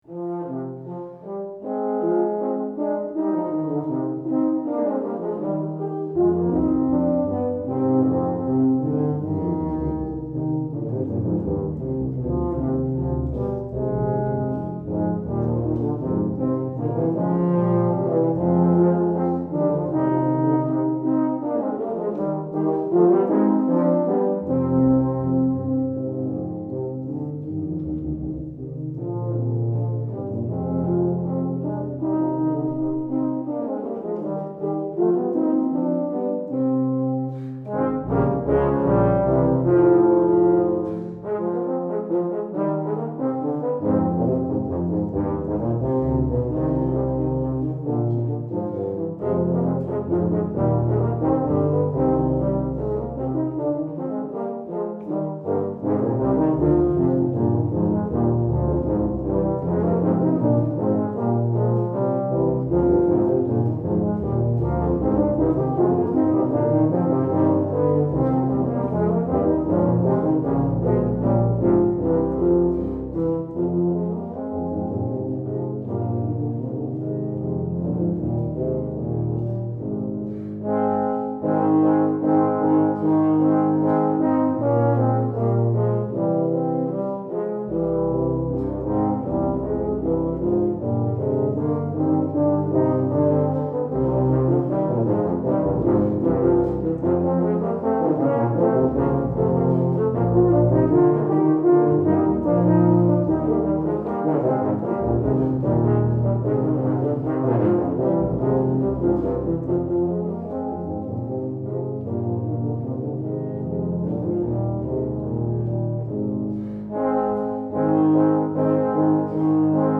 Voicing: Tuba Ens